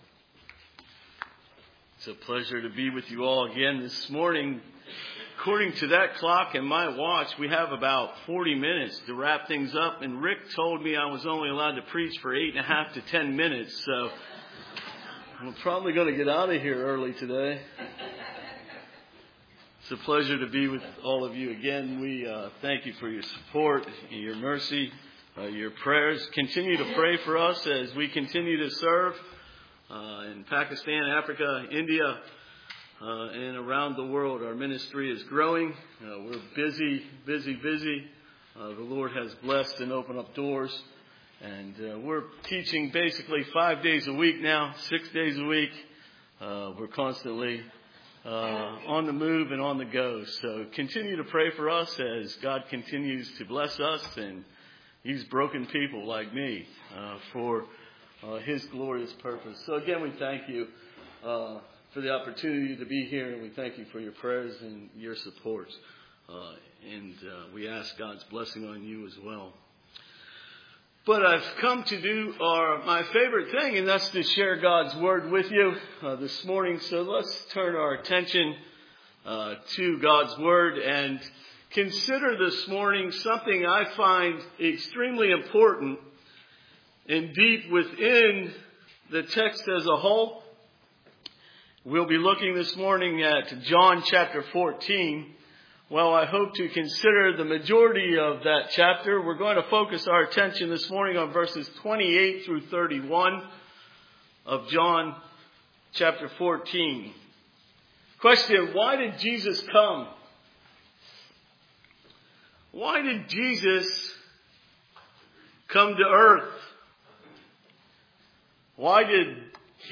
John 14:28-31 Service Type: Sunday Morning John 14:28-31 Why did Jesus come to earth?